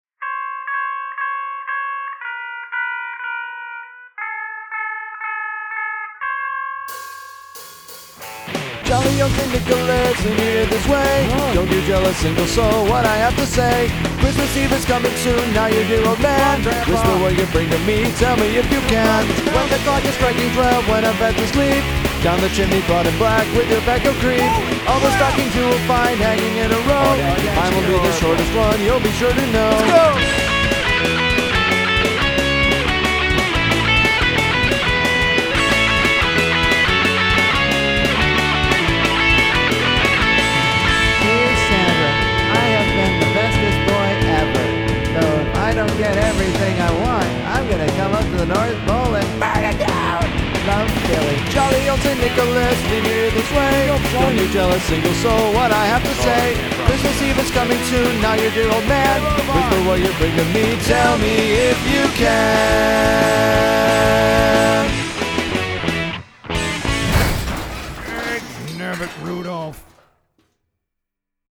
punk rock
Since I now live in an apartment in central Ohio, I could not use real drums this time – these drums are all as a result of Toontrack programs. Everything else is real.
Mixed at Thea Partment, Columbus, OH.